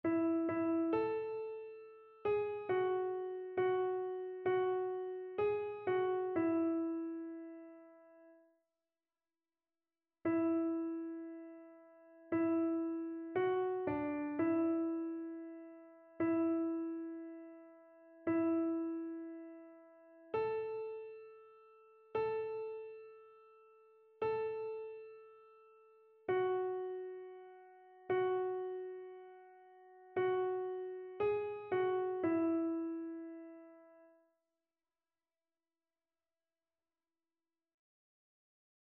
Soprano
annee-abc-temps-de-noel-nativite-du-seigneur-psaume-88-soprano.mp3